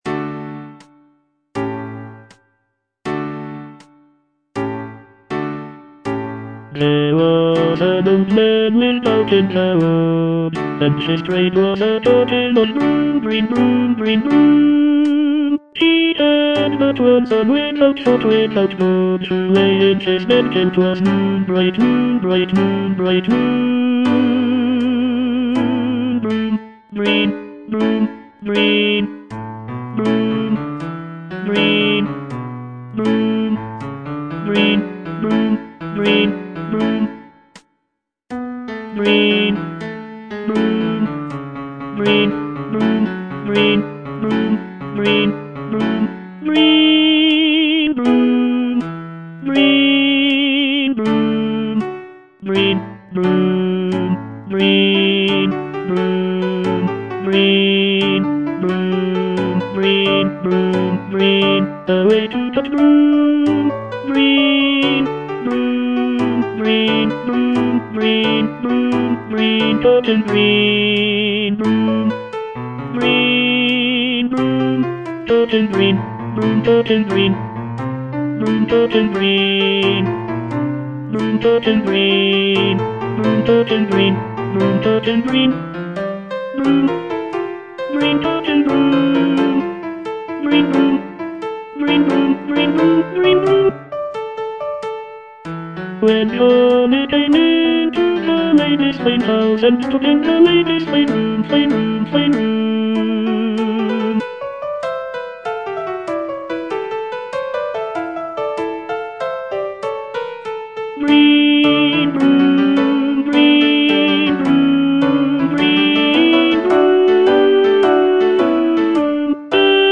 Tenor I (Voice with metronome)
is a folk song